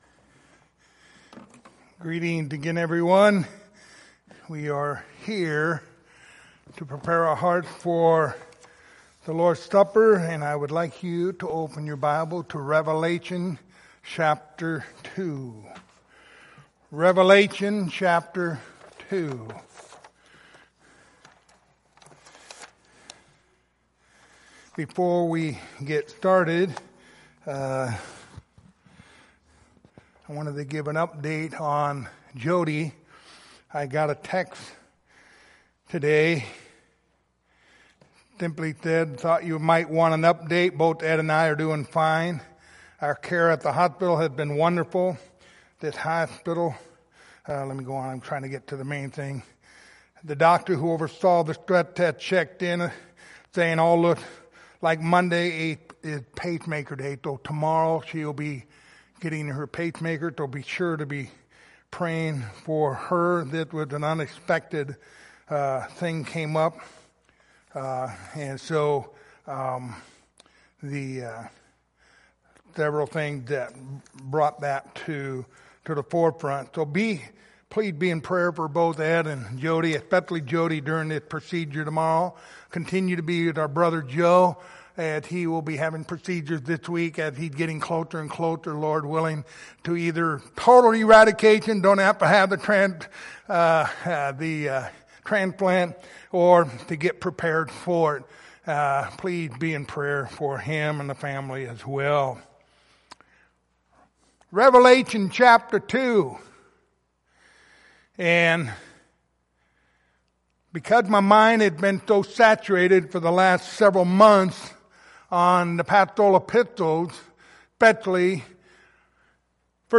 Revelation 2:1-7 Service Type: Lord's Supper Topics